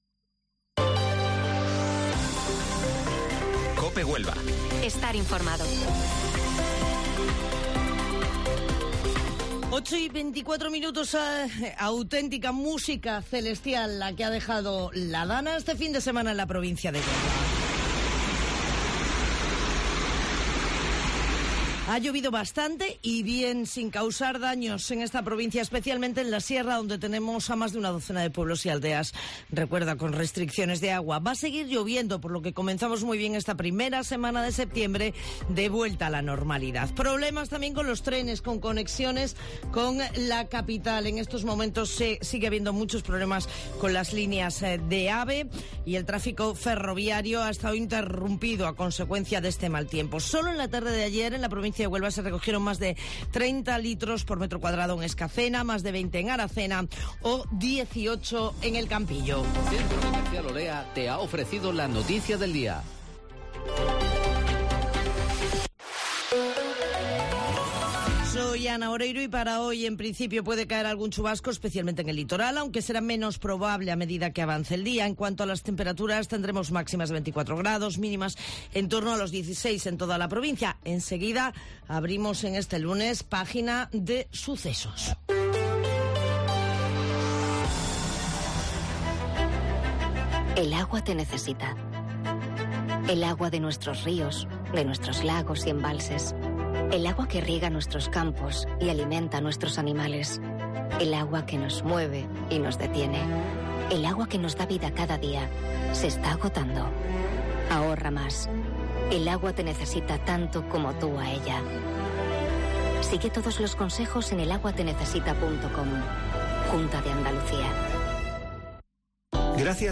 Informativo Matinal Herrera en COPE 4 de septiembre